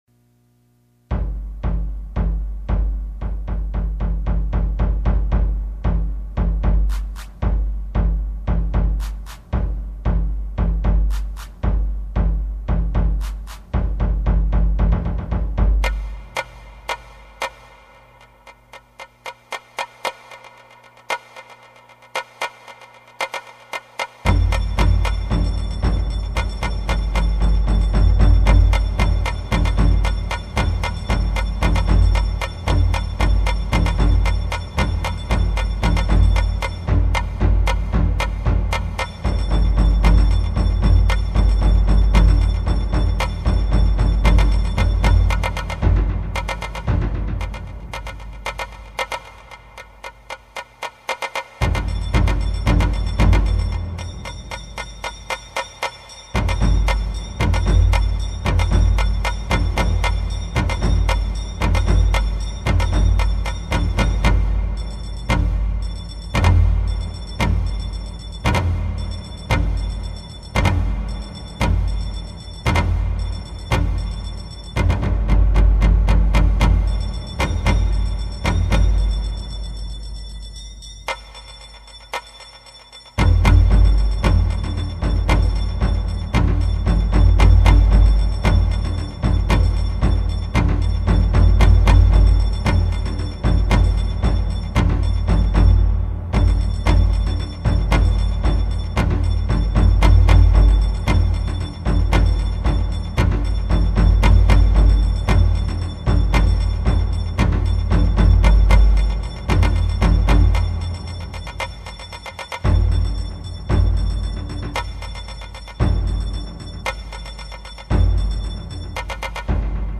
使用楽器　　大太鼓・中太鼓・締太鼓・当たり鉦